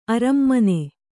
♪ arammane